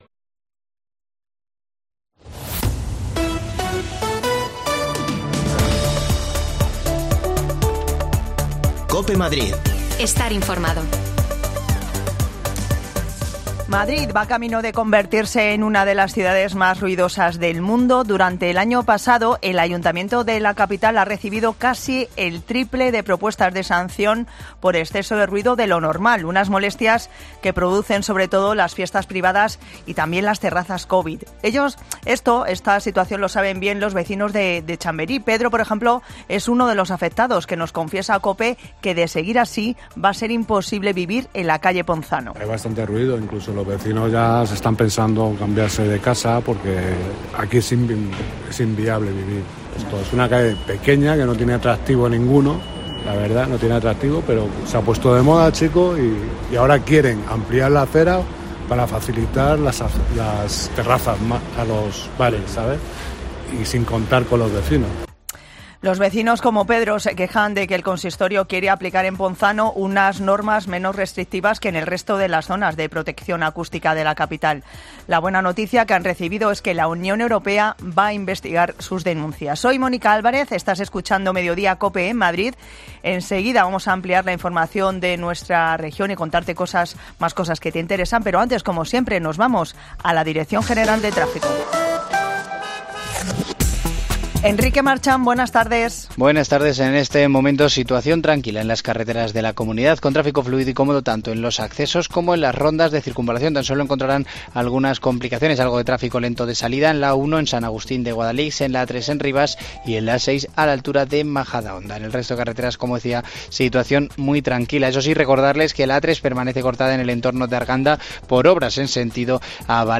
AUDIO: Madrid lleva camino de ser una de las ciudades más ruidosas del mundo... Hablamos con vecinos que sufren el ruido dia y noche cerca de sus...